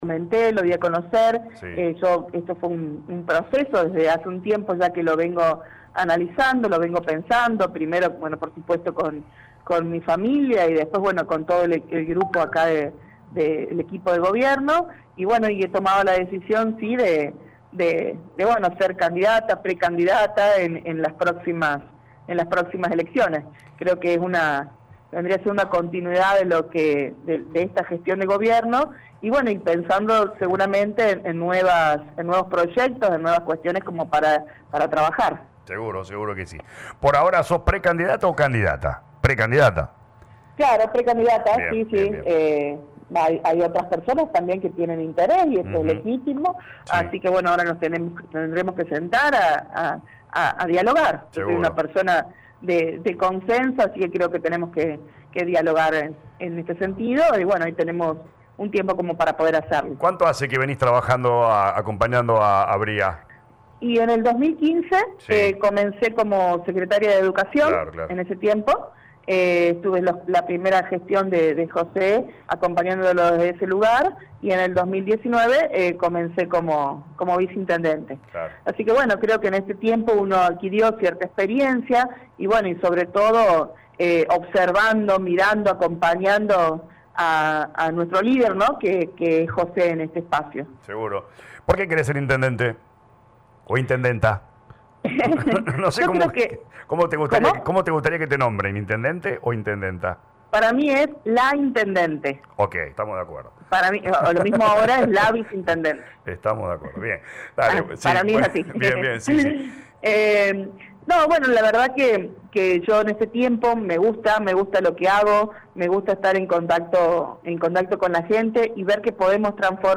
La Viceintendente habló en LA RADIO 102.9 y expresó  «lo charle con mi familia en primer lugar y después con el grupo que compartimos la gestión y otras que no, y he decidido presentarme y ser candidata a intendente en las próximas elecciones municipales”.